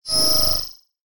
tpad_teleport.ogg